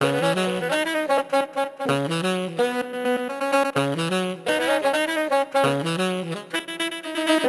Moustache_C#_128_Dry.wav